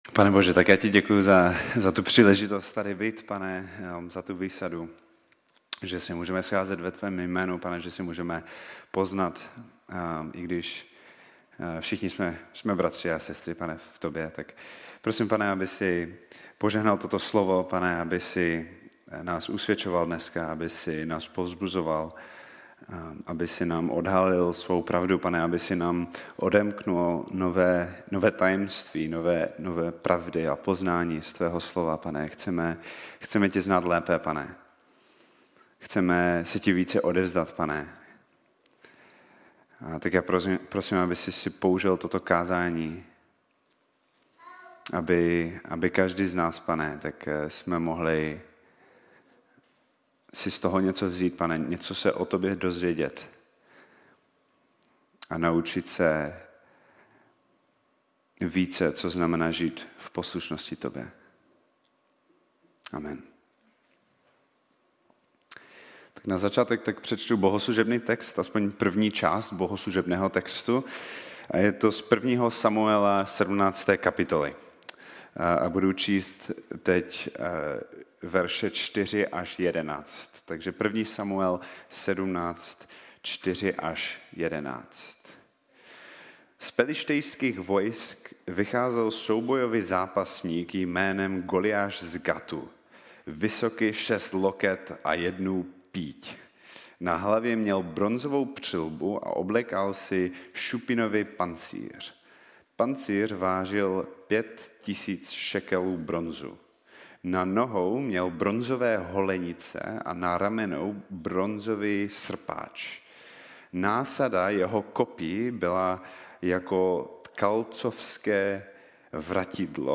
1 Kázání